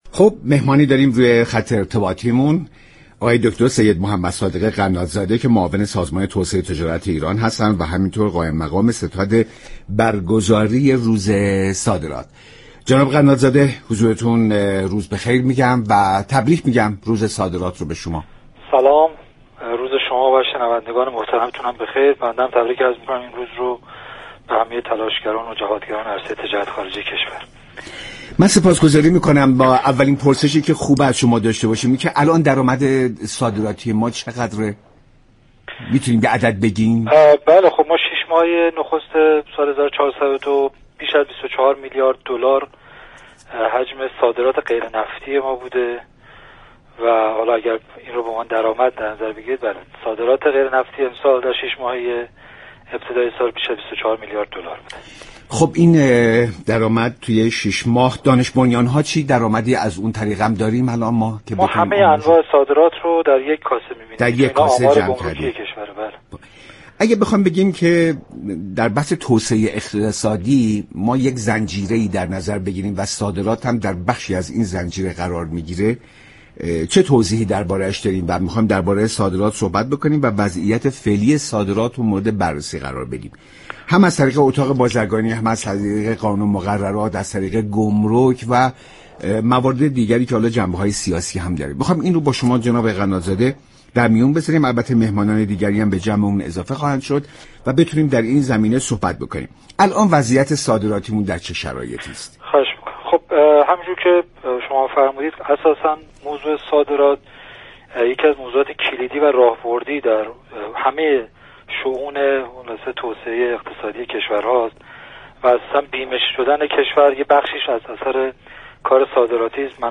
به گزارش شبكه رادیویی ایران، سید محمد صادق قنادزاده معاون سازمان توسعه تجارت ایران در برنامه ایران امروز به درآمدهای صادراتی كشور اشاره كرد و گفت: در شش ماهه نخست امسال، حجم صادرات غیر نفتی ایران بیش از 24 میلیارد دلار بوده است.